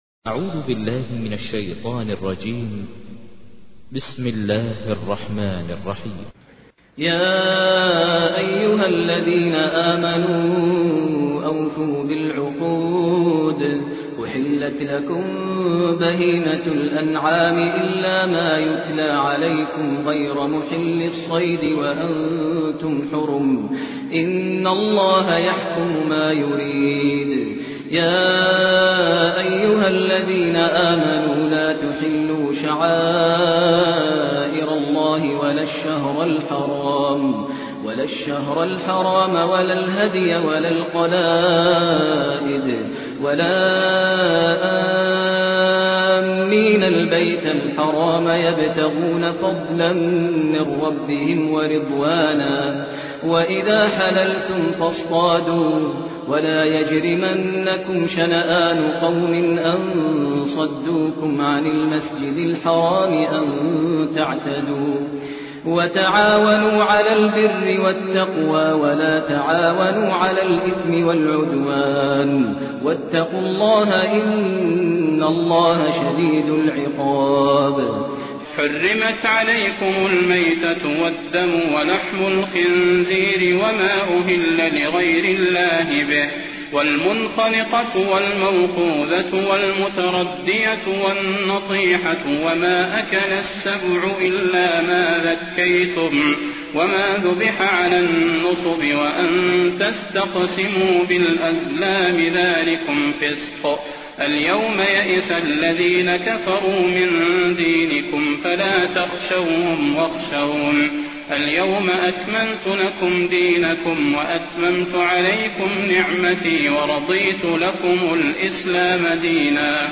تحميل : 5. سورة المائدة / القارئ ماهر المعيقلي / القرآن الكريم / موقع يا حسين